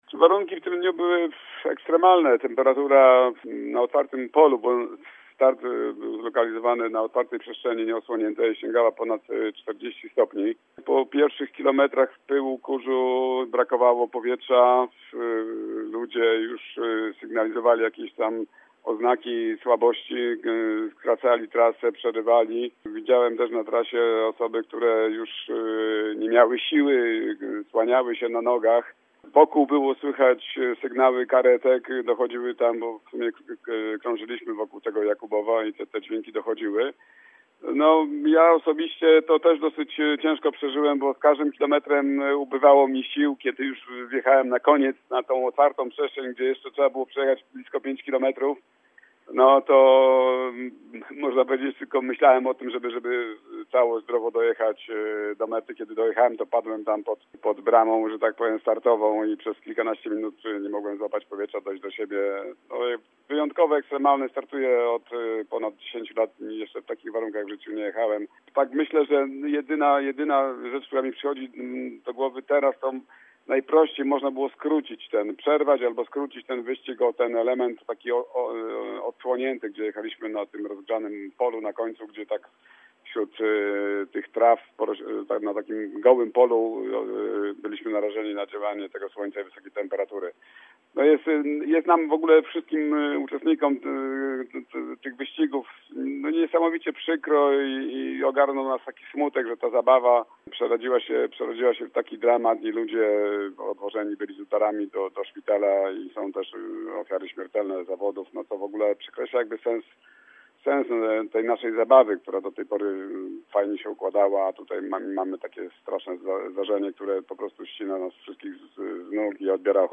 Jednym ze stałych uczestników imprez GP Kaczmarek Electric MTB jest zielonogórzanin